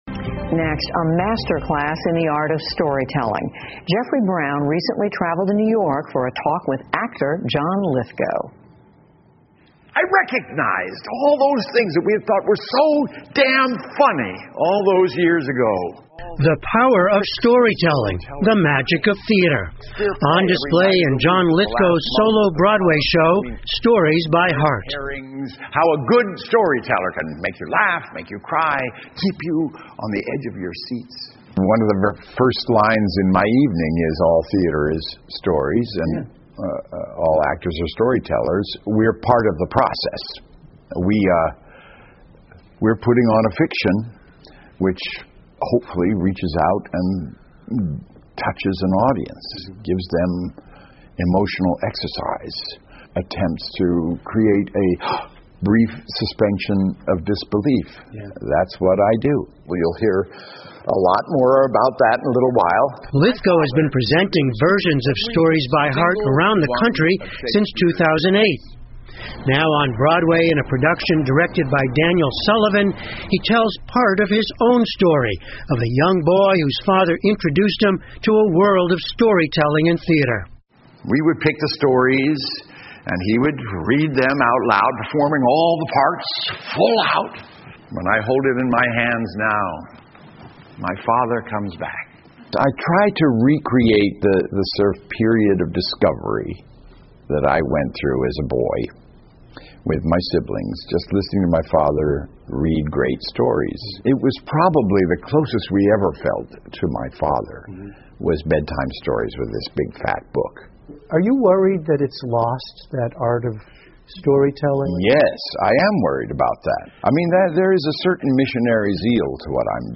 PBS高端访谈:约翰·利斯高将睡前故事搬上百老汇 听力文件下载—在线英语听力室